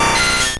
Cri de Scarabrute dans Pokémon Or et Argent.